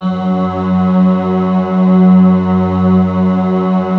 Index of /90_sSampleCDs/Club-50 - Foundations Roland/VOX_xScats_Choir/VOX_xSyn Choir 1